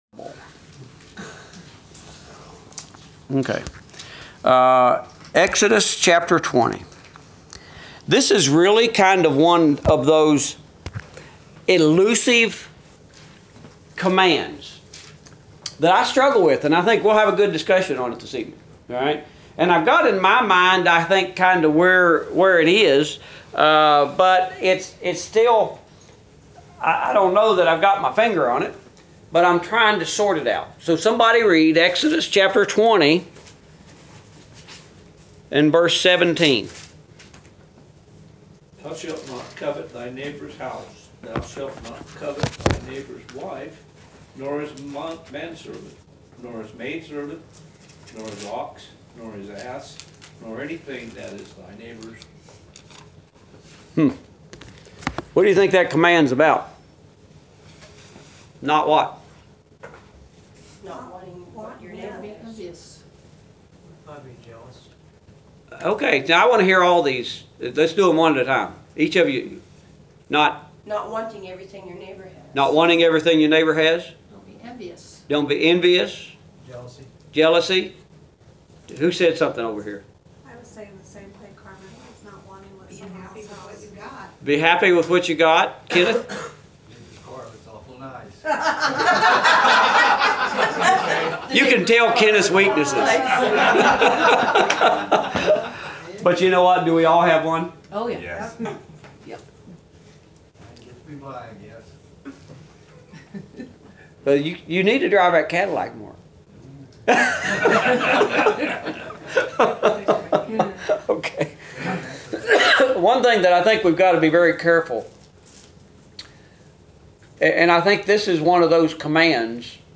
Adult Bible Class: 11/22/17